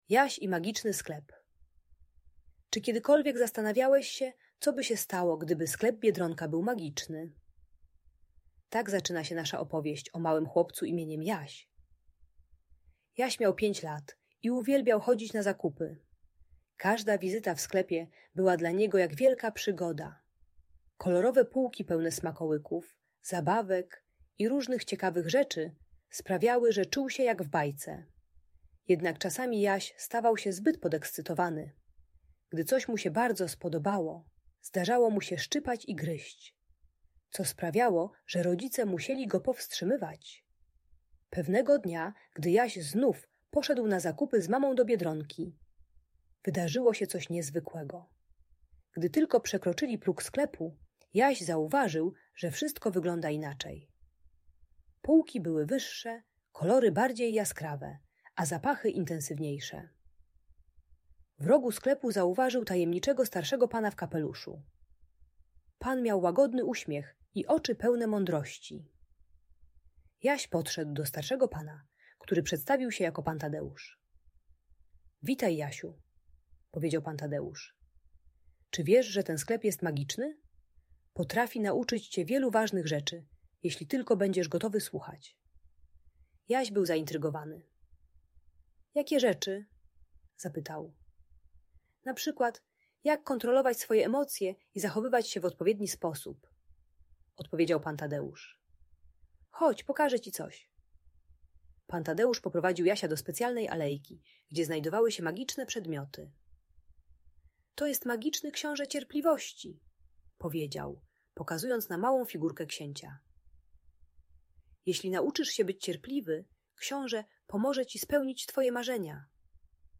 Jaś i Magiczny Sklep - Bunt i wybuchy złości | Audiobajka